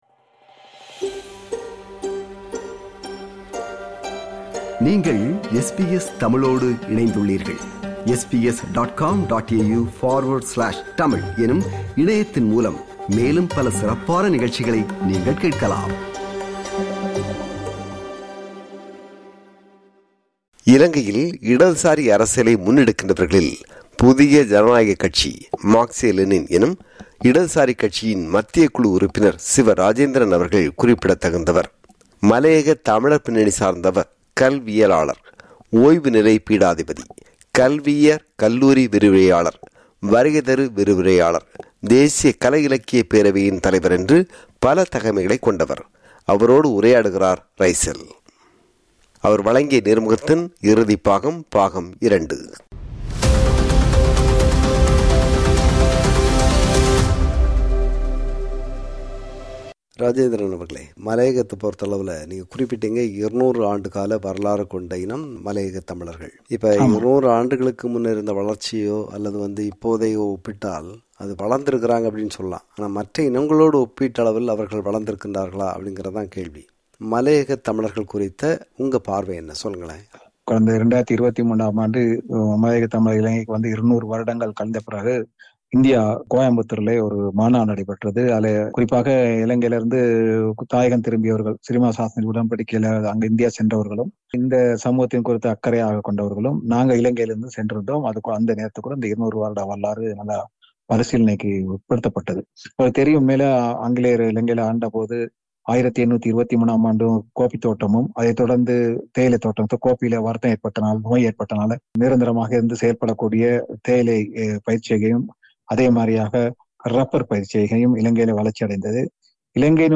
நேர்முகம் – பாகம் 2.